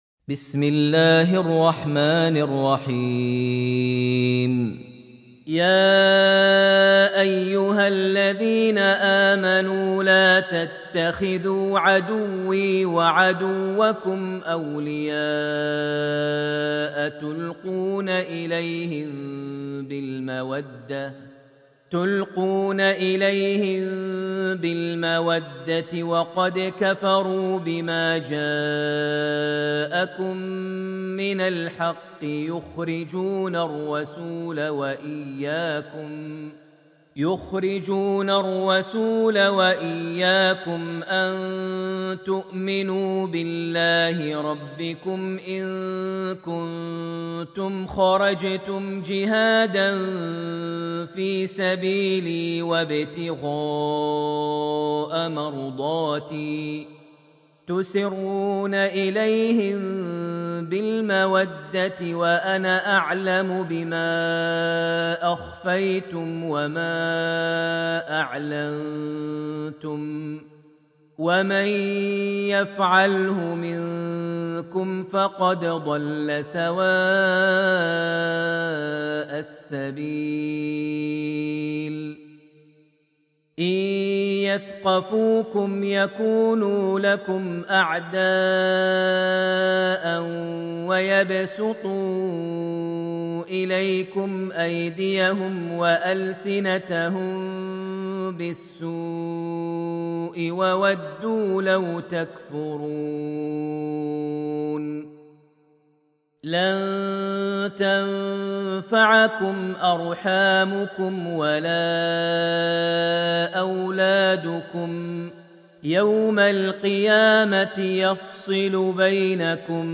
Surah Al-Mumtahinah > Almushaf > Mushaf - Maher Almuaiqly Recitations